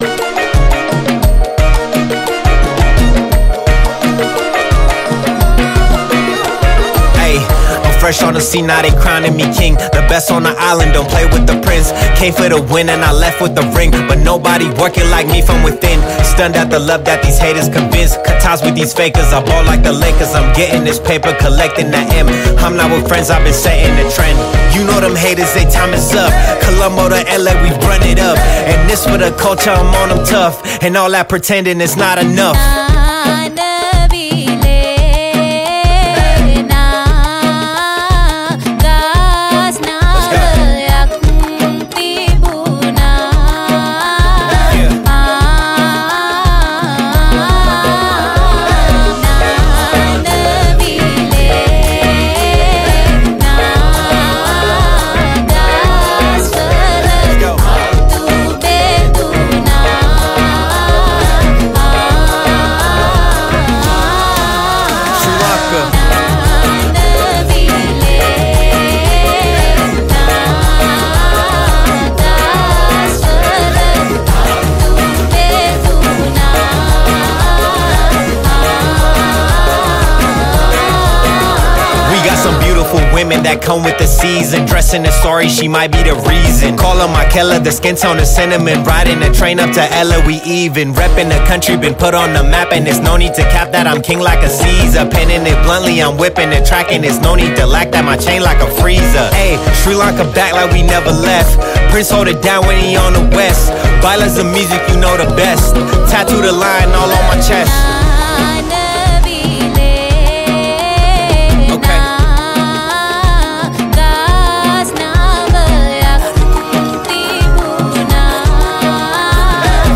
Releted Files Of Sinhala New Rap Mp3 Songs